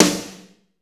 Index of /90_sSampleCDs/Northstar - Drumscapes Roland/DRM_Slow Shuffle/KIT_S_S Kit 1 x